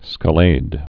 (skə-lād, -läd) also sca·la·do (-lādō, -lä-)